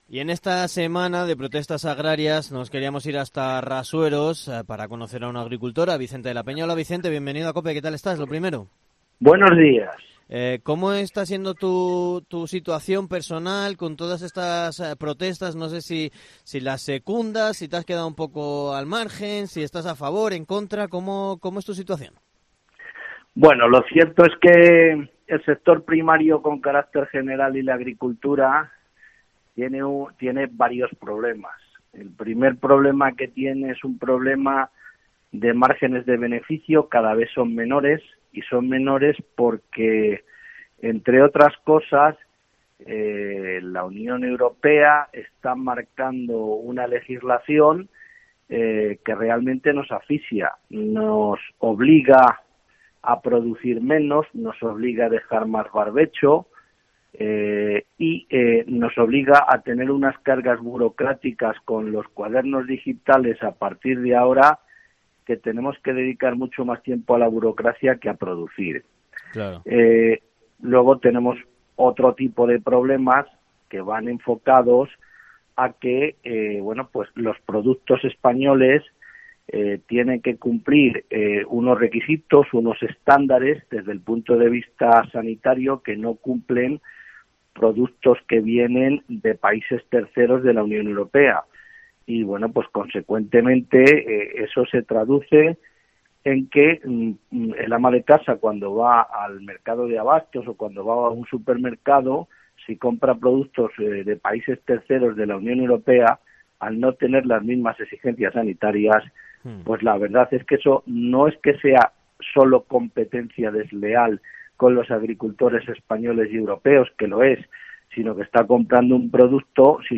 ENTREVISTA / Agricultores abulenses en COPE